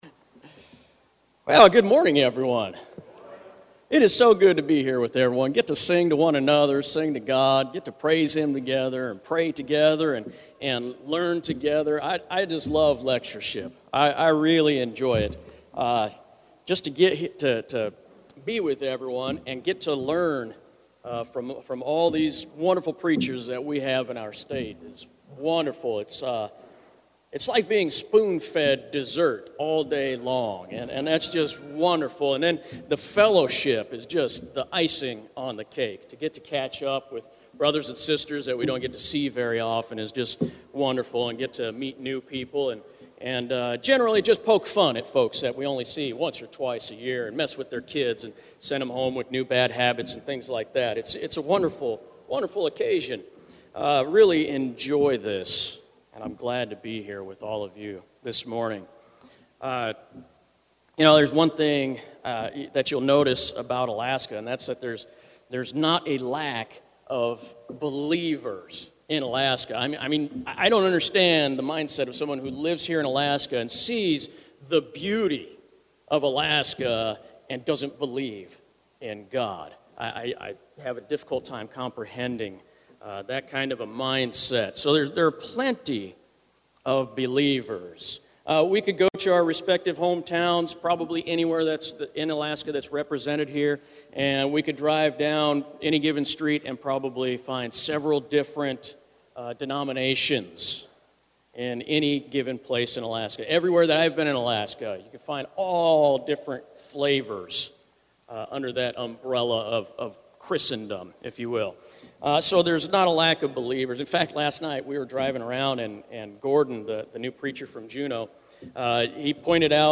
Valley church of Christ - Matanuska-Susitna Valley Alaska
Lectureship